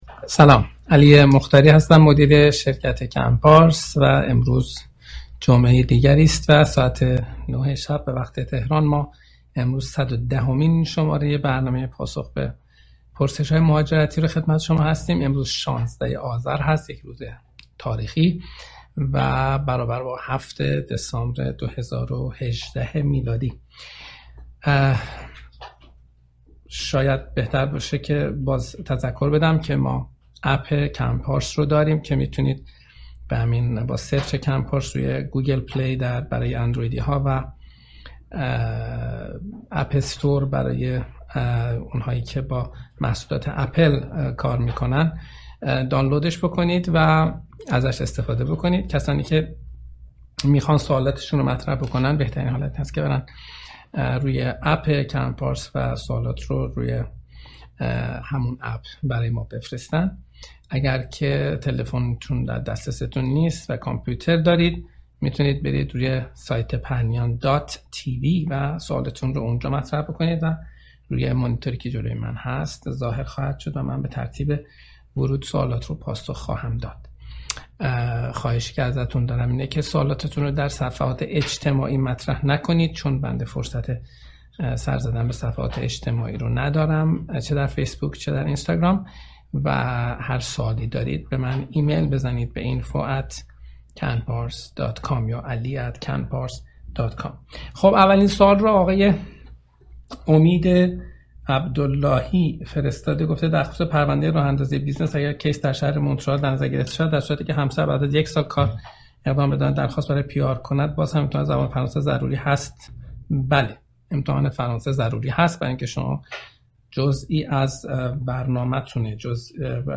برنامه زنده پاسخ به سوالات مهاجرتی